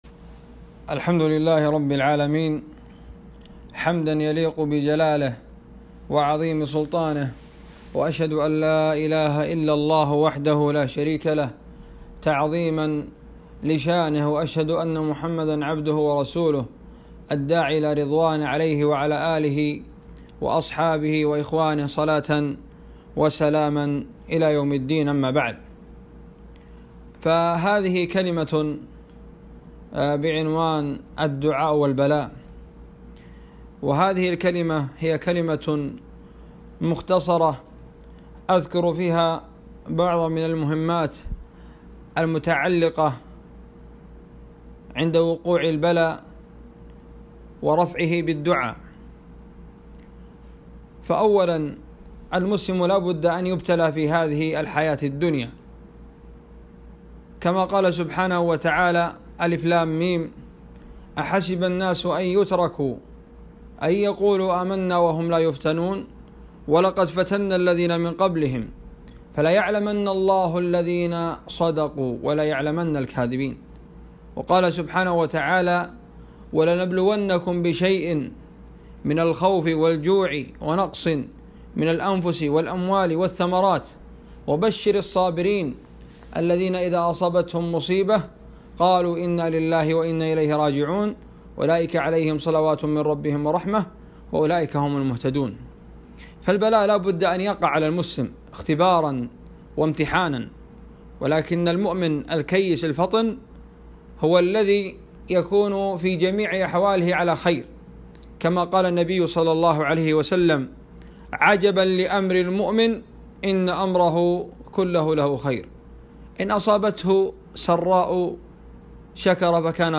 محاضرة - الدعاء عند البلاء في القرآن والسنة